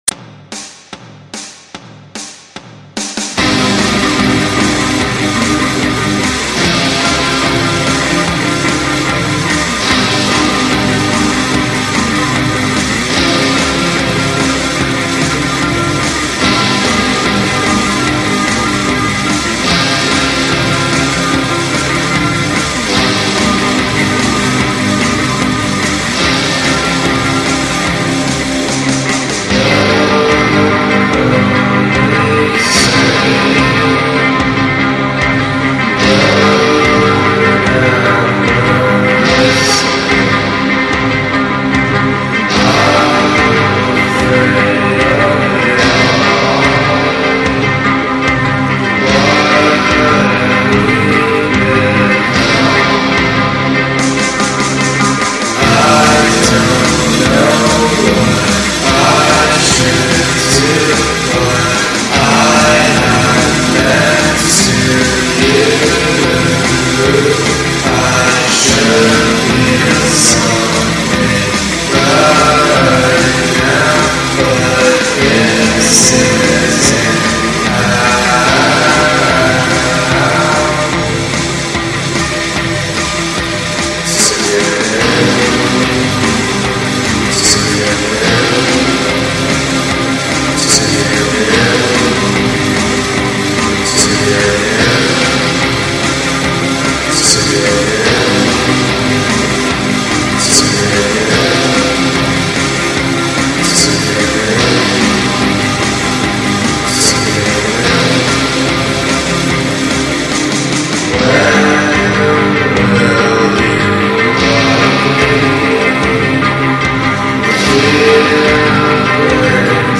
i demo da cameretta